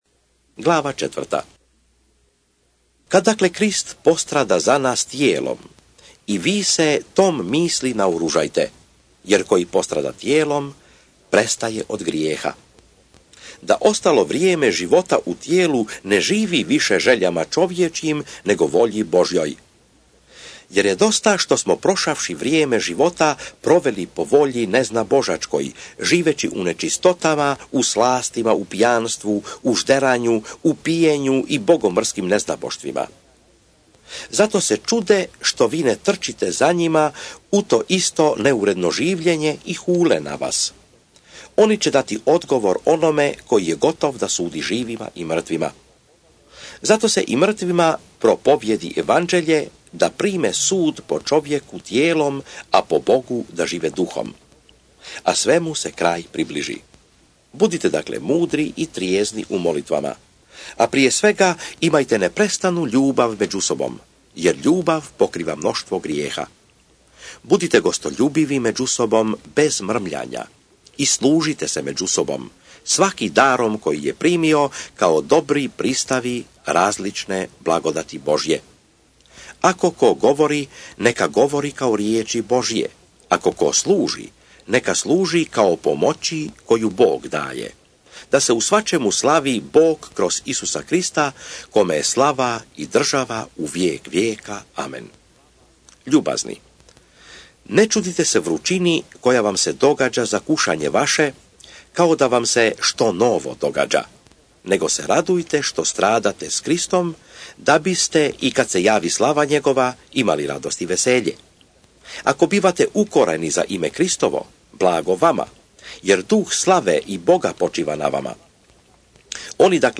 SVETO PISMO – ČITANJE – Audio mp3 1 PETAR glava 1 glava 2 glava 3 glava 4 glava 5